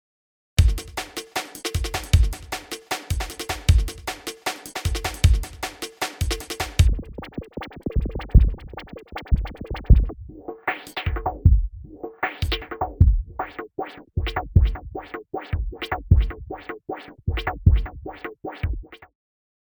フィルターフィルター
一定の周波数以上をカットするローパスフィルターや、逆に一定の周波数以下をカットするハイパスフィルターが一般的。前者は「モコモコ」。後者は「シャリシャリ」とした音になる。